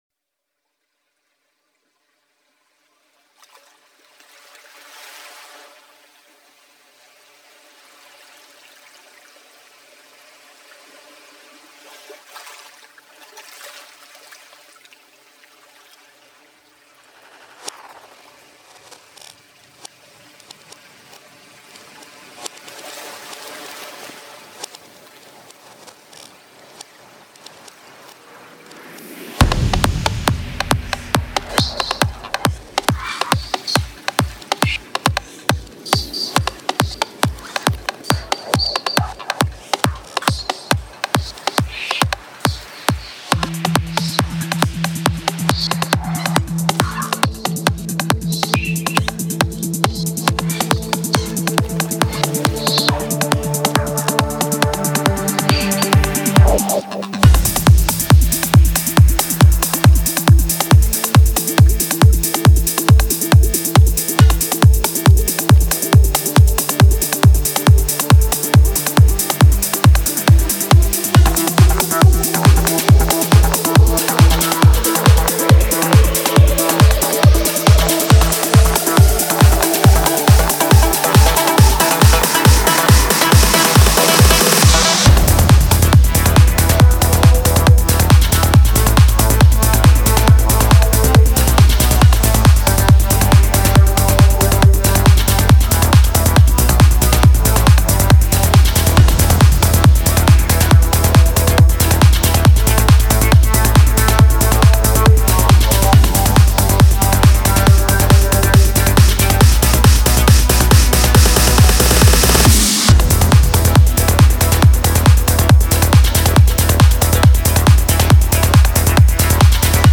A fun loving "Surf Trance" tune!